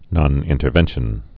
(nŏnĭn-tər-vĕnshən)